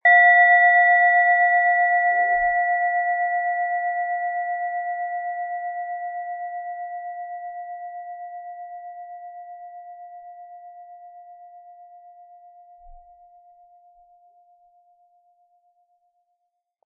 Planetenschale® Harmonie erspüren & Energie entfachen mit Wasser-Ton, Ø 11,5 cm inkl. Klöppel
Aber uns würde der kraftvolle Klang und diese außerordentliche Klangschwingung der überlieferten Fertigung fehlen.
Mit dem beigelegten Klöppel können Sie je nach Anschlagstärke dominantere oder sanftere Klänge erzeugen.
MaterialBronze